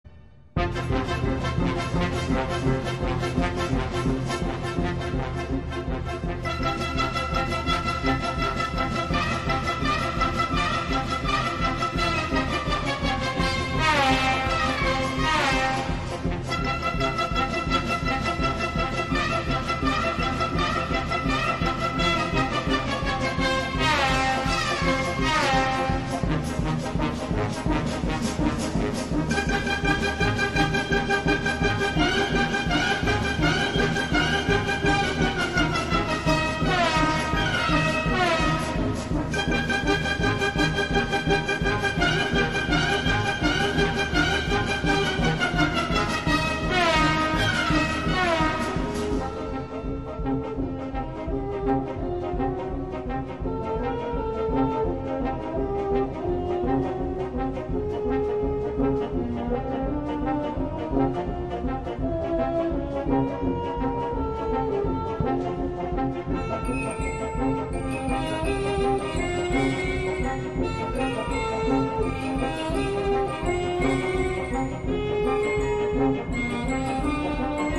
军乐团最强阵容演奏